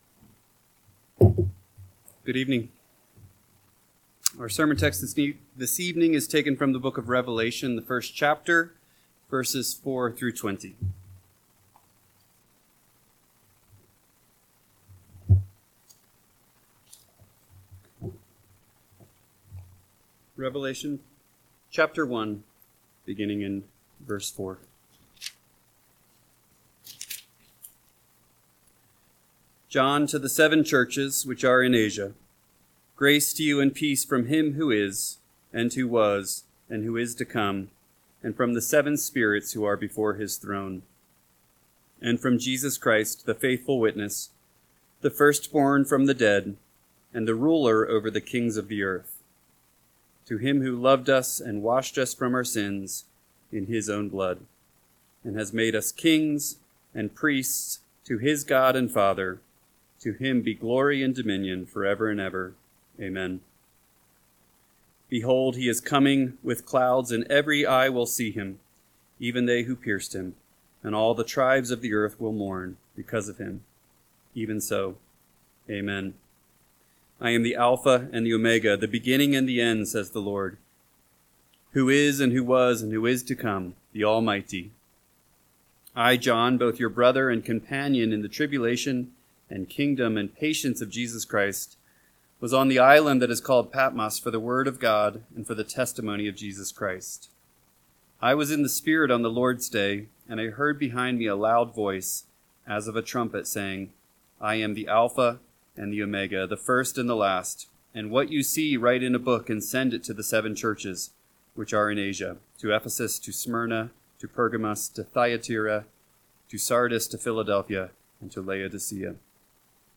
PM Sermon – 8/24/2025 – Revelation 1:4-20 – Northwoods Sermons